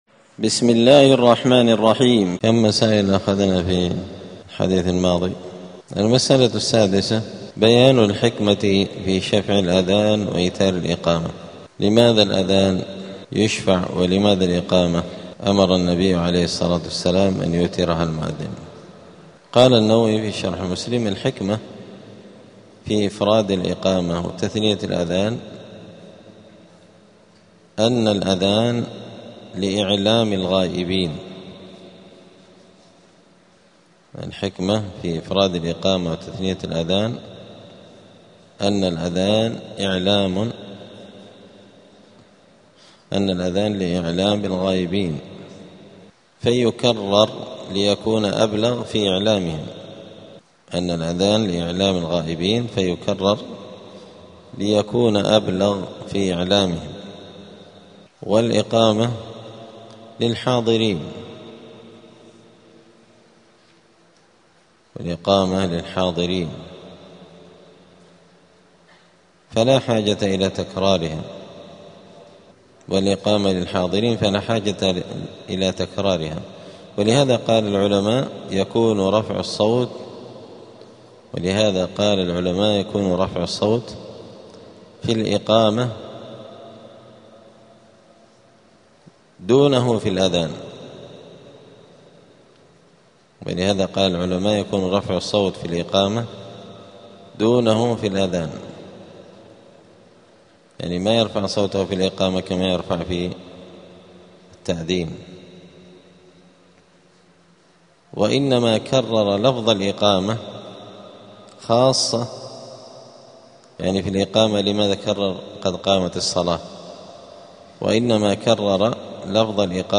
دار الحديث السلفية بمسجد الفرقان قشن المهرة اليمن
*الدرس الثامن والأربعون بعد المائة [148] {بيان الحكمة في شفع الأذان وإيتار الإقامة}*